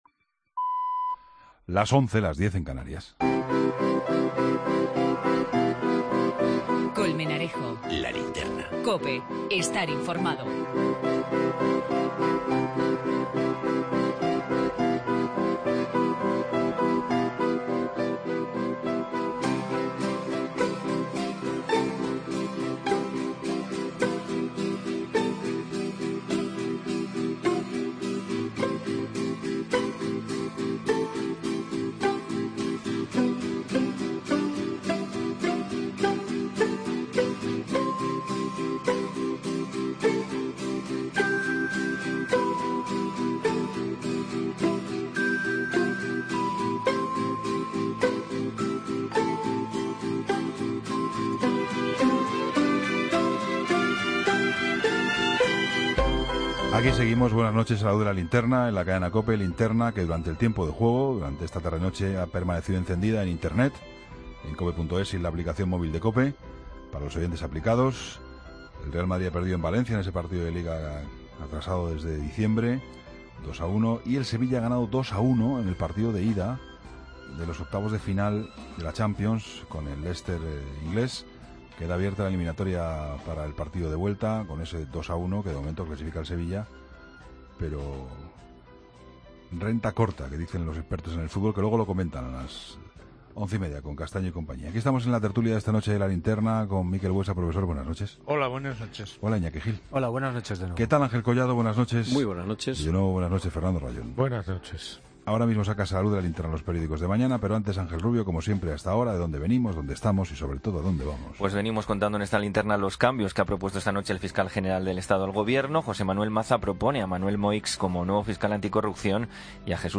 Tertulia II, miércoles 22 de febrero de 2017